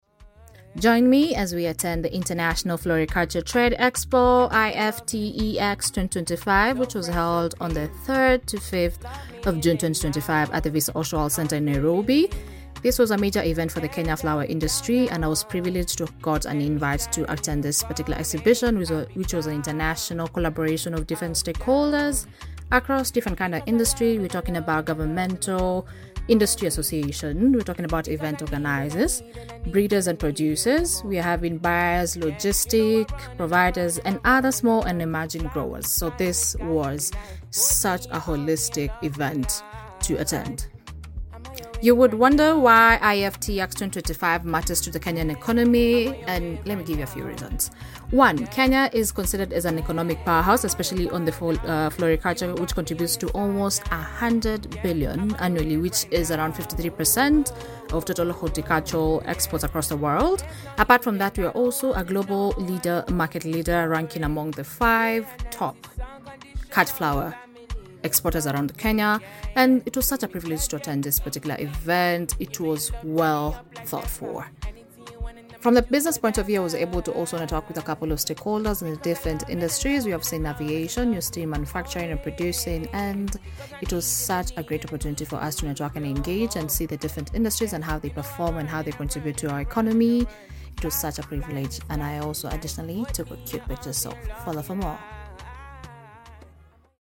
International Floriculture Trade Expo IFTEX 2025 held at Visa Oshwal Nairobi Kenya